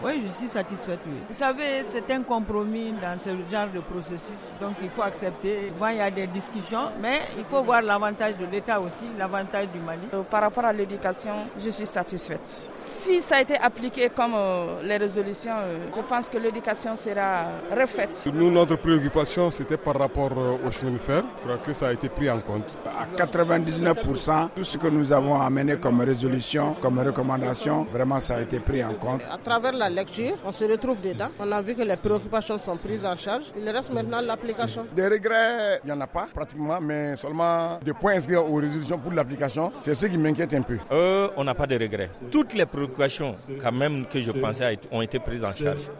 MICROTTROTOIR.mp3